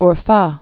(r-fä)